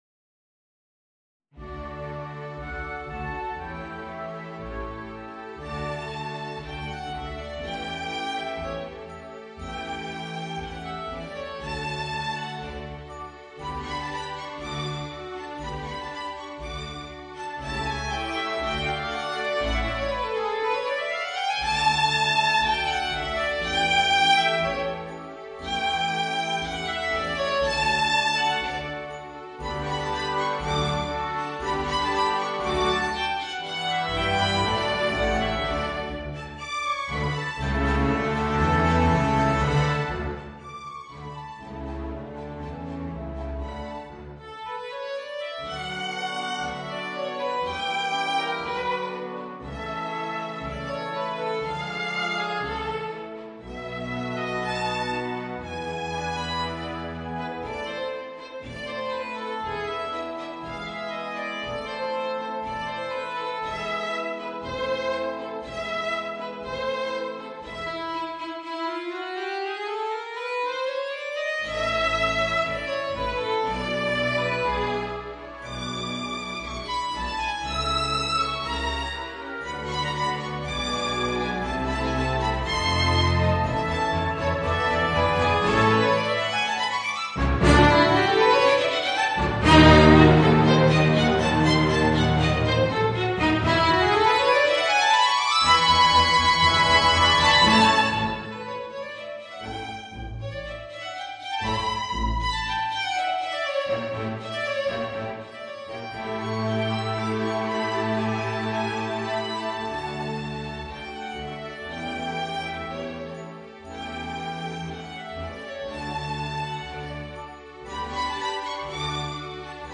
Voicing: Violin and Orchestra